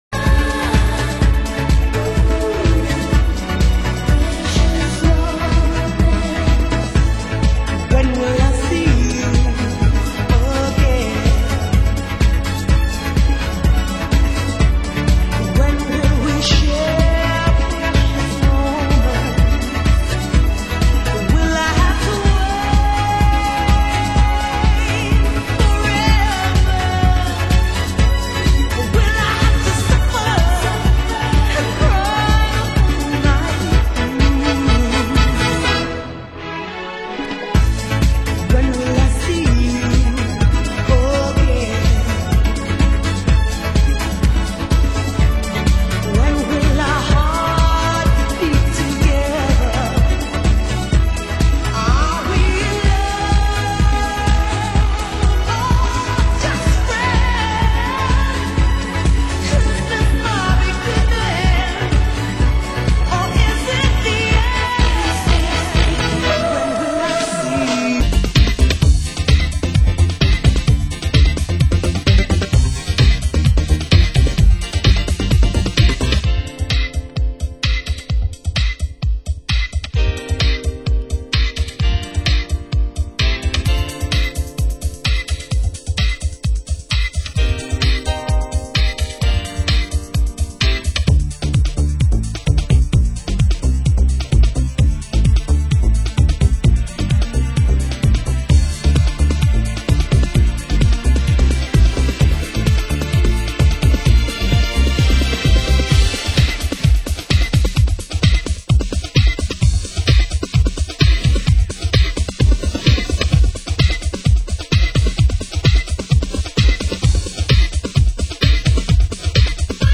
Format: Vinyl 12 Inch
Genre: UK House